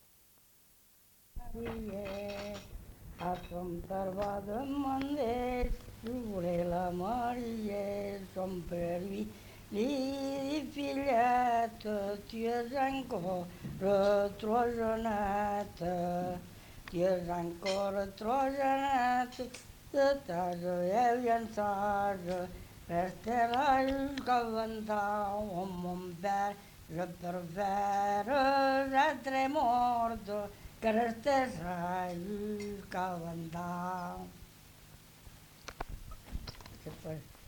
Genre : chant
Effectif : 1
Type de voix : voix d'homme
Production du son : chanté